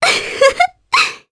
Ophelia-Vox-Laugh_1_jp.wav